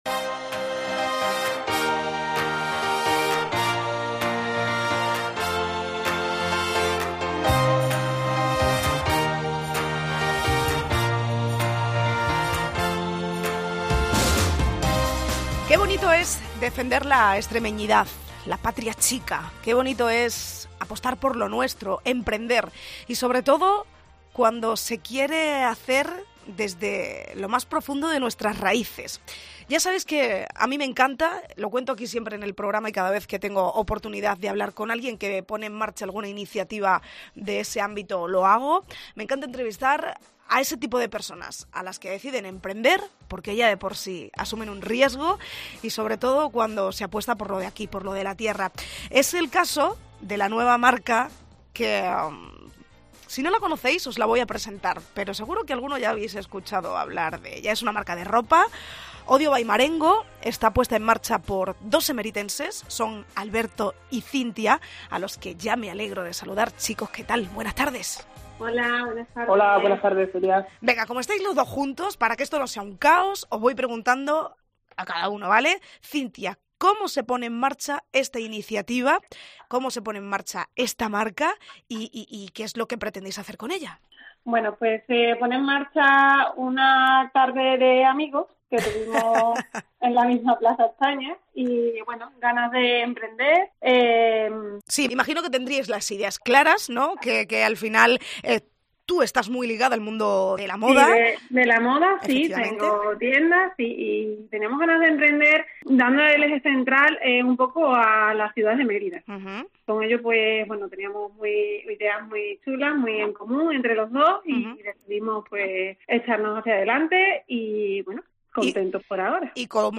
Entrevista con ODIO BY MARENGO